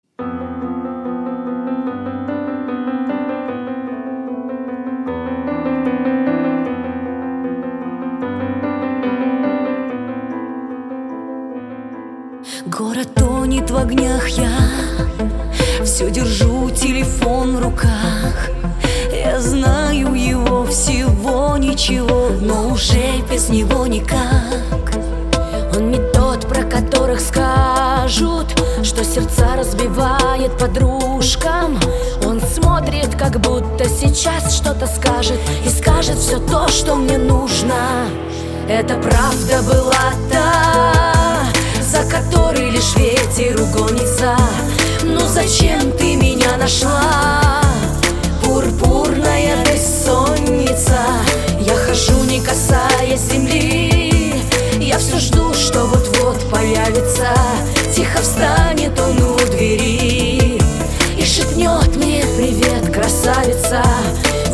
• Качество: 256, Stereo
красивые
женский вокал
грустные
спокойные
пианино